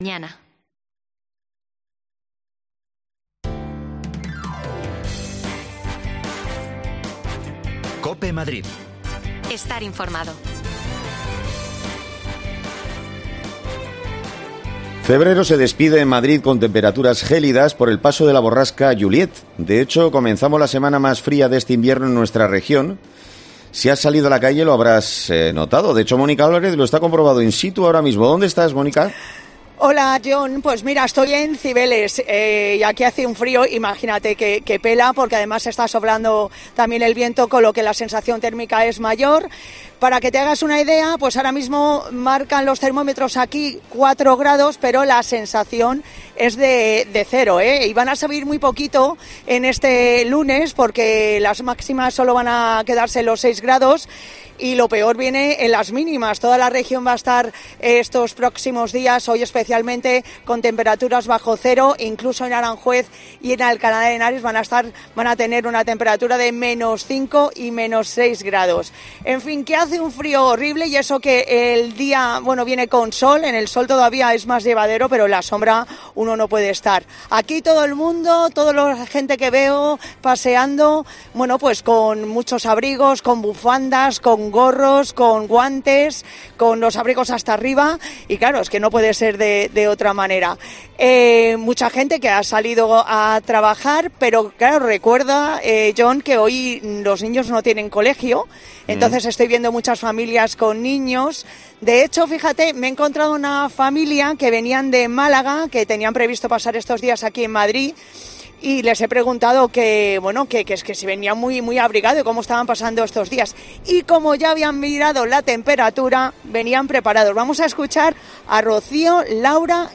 AUDIO: Madrid despide febrero con temperaturas gélidas. Salimos a la calle y lo comprobamos...